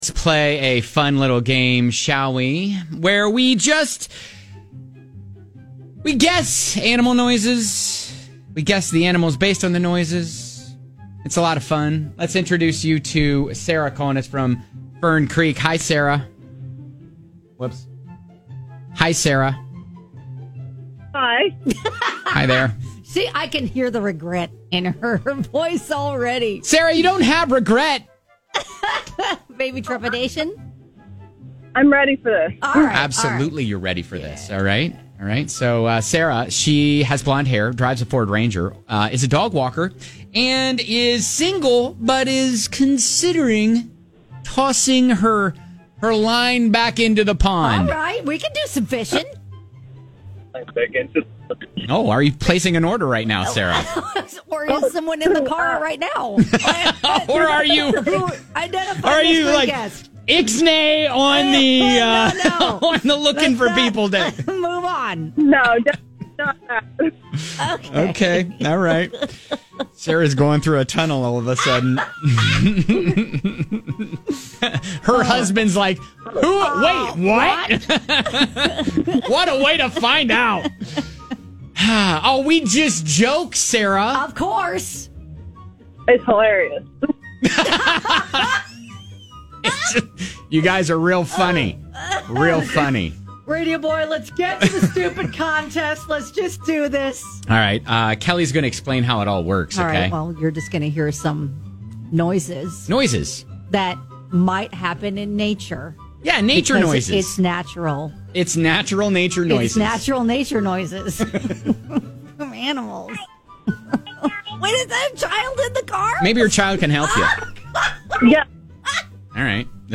Can you identify the animal based on the noise they make... mating?!?! lol